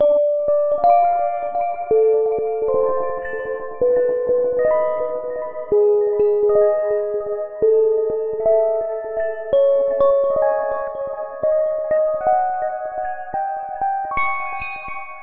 描述：用Omnisphere制作 声音看起来像xxx的月光
Tag: 126 bpm Trap Loops Bells Loops 2.56 MB wav Key : Unknown FL Studio